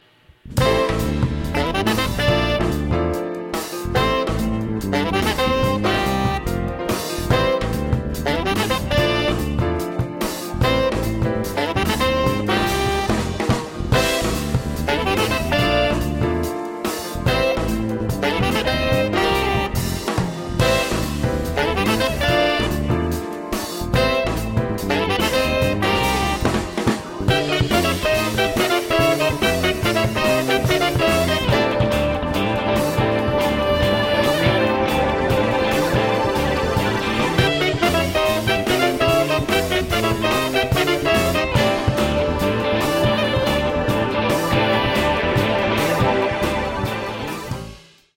piano, guitar, synths
• ARP 2600V (Intro FX)
• Arturia CS-80V (7/8 pads, solo harmony)
• G-Force ImpOscar (solo)
• Kurzweil PC2 (organ)
• Waldorf Microwave Xt (solo)
soprano and tenor saxes
electric bass
drums